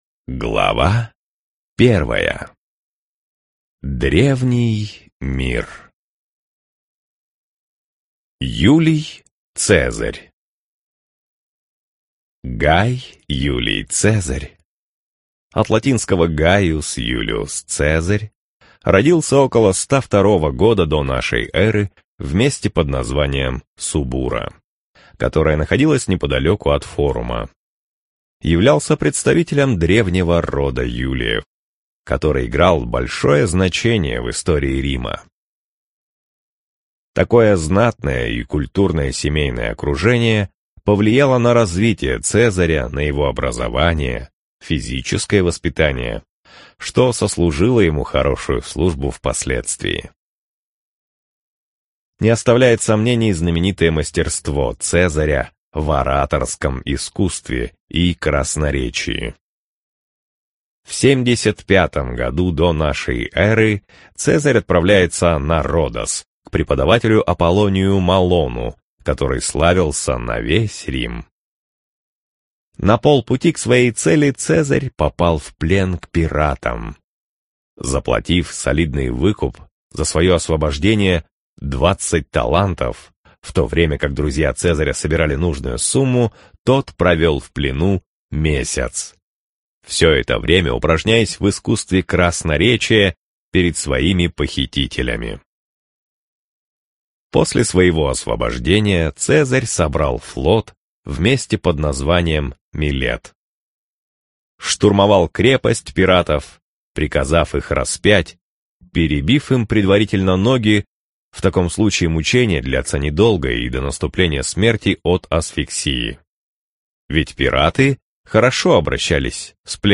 Аудиокнига Мужчины, изменившие мир | Библиотека аудиокниг